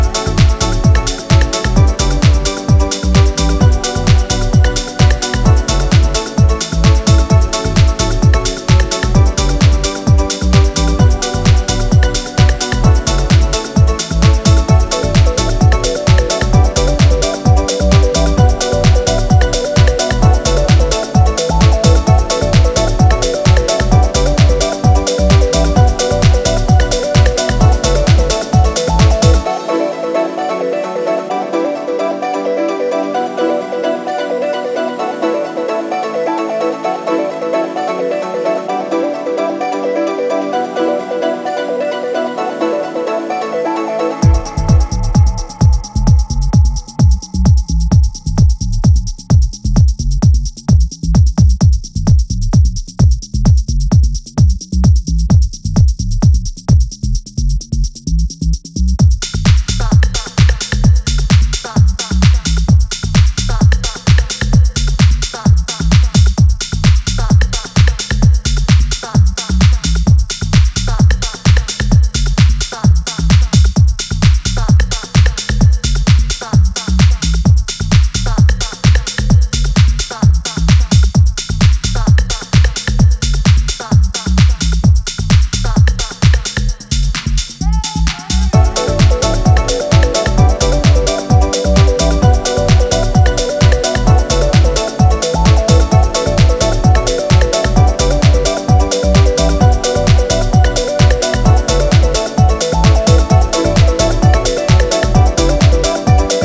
richly textured, retro-leaning, and irresistibly danceable